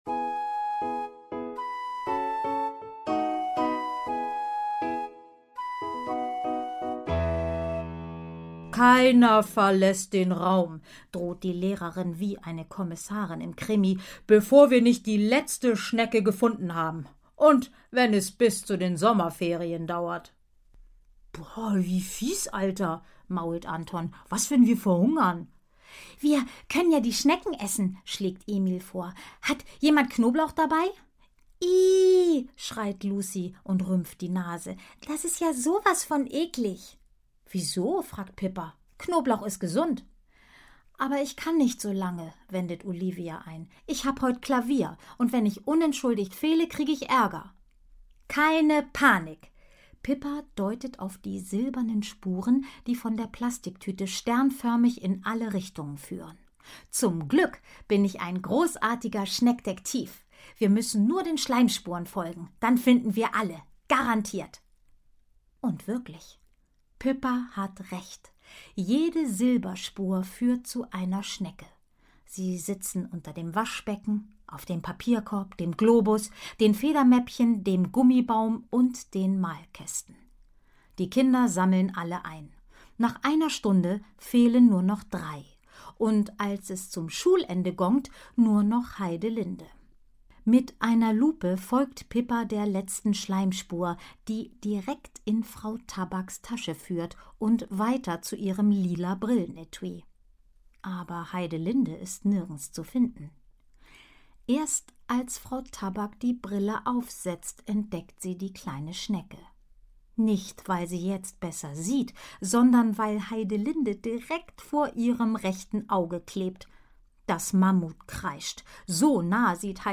Hörbuch, 1 CD, 79 Minuten